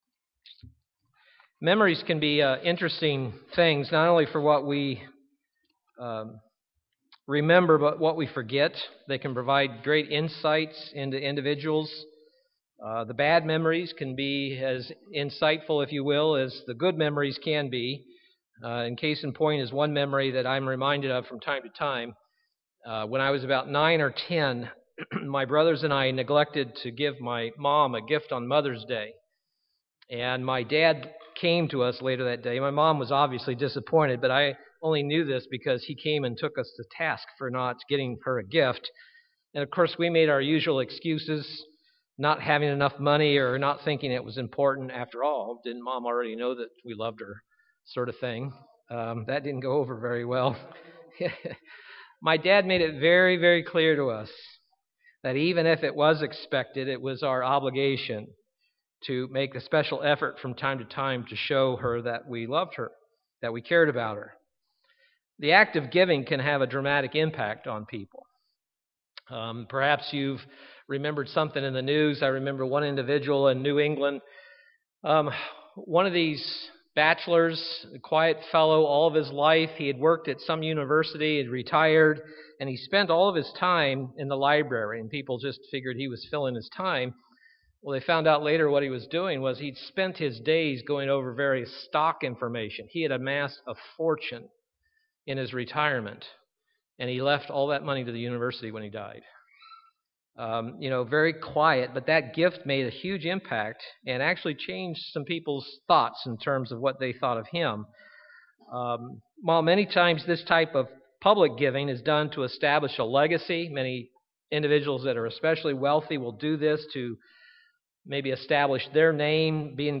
Print Giving is part of God's character and should be part of our life as a Christian UCG Sermon Studying the bible?